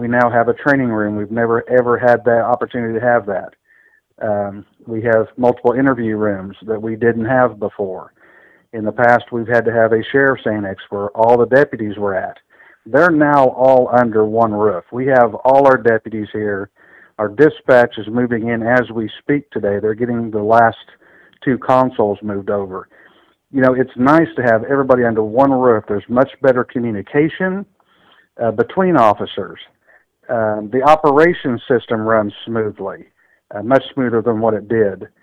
Johnson says the new building helps to bring all law enforcement operations under one roof, with administrative services, investigations and the jail on the ground floor and County Attorney Wade Bowie’s operations on the second level.